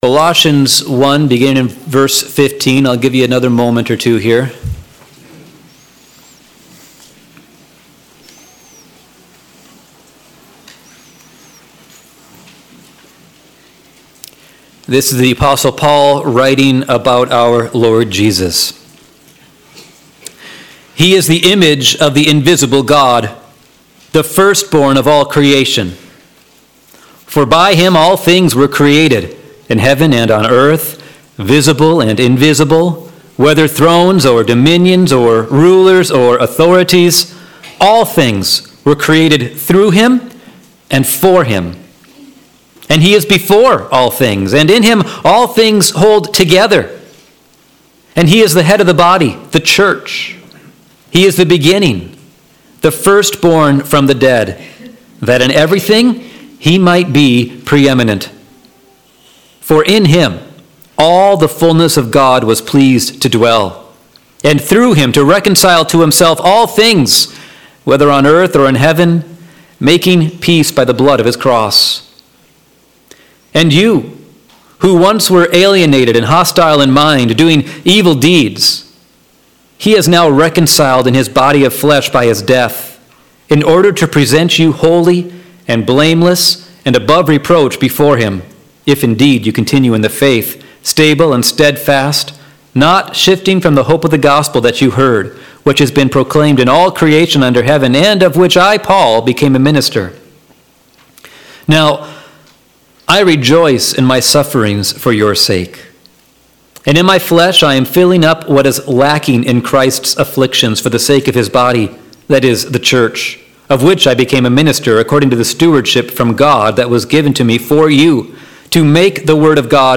Sermons | Rothbury Community Church
Missions Conference